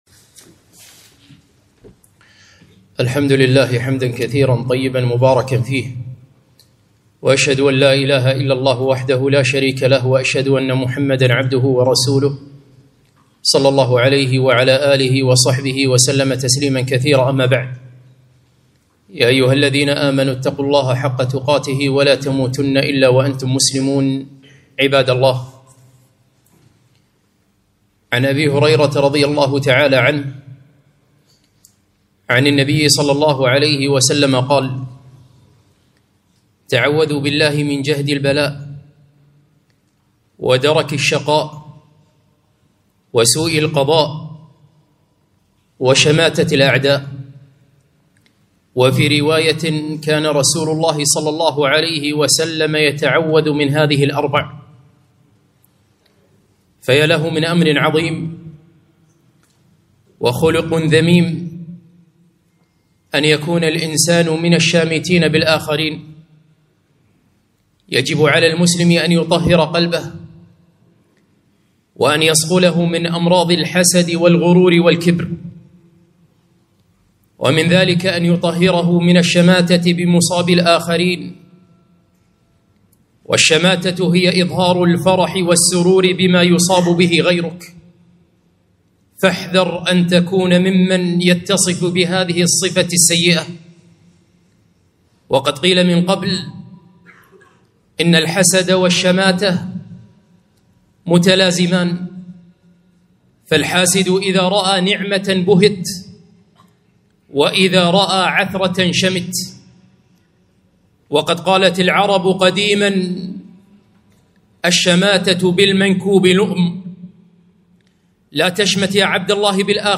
خطبة - الشماتة بالآخرين